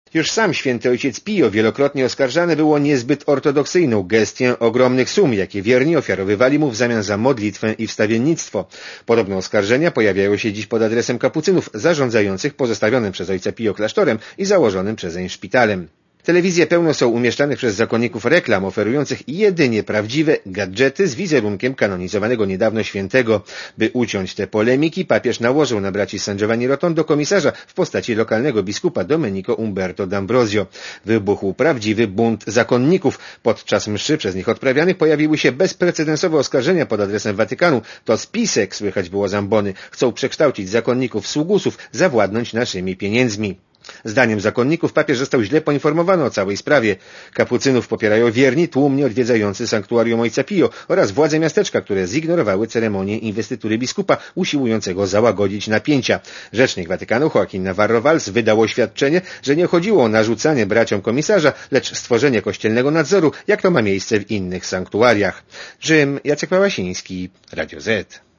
Posłuchaj relacji korespondenta Radia Zet (270 KB)